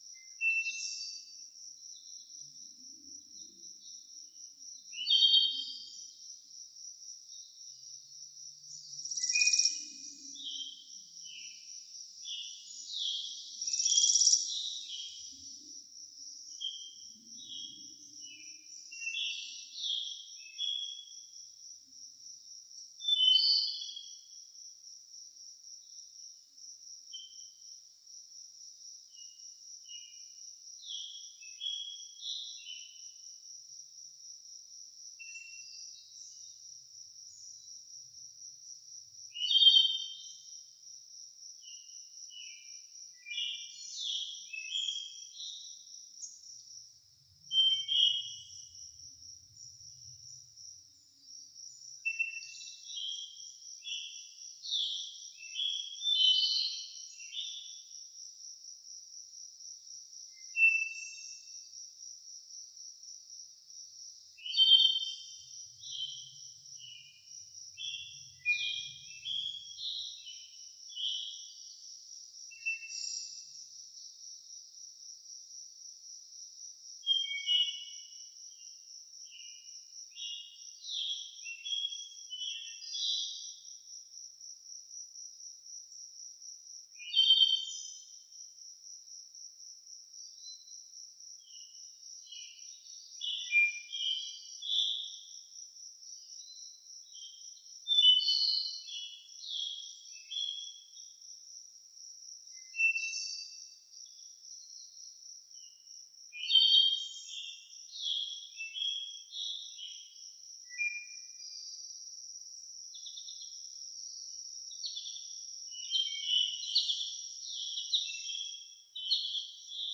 Звук гармоний природы